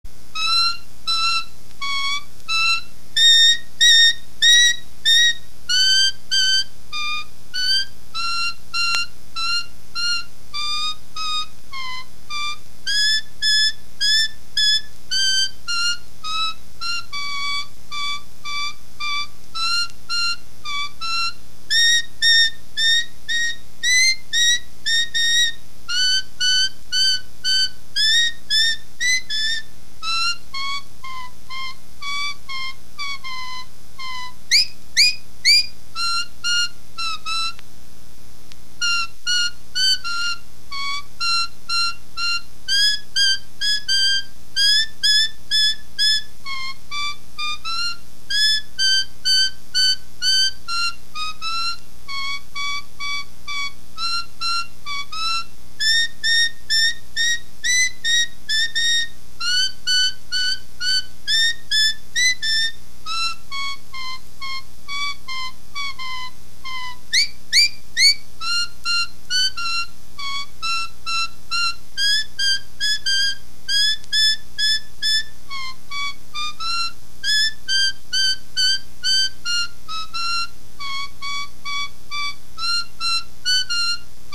himno religioso protestante
Música afrocaribeña: himnos religiosos y marchas fúnebres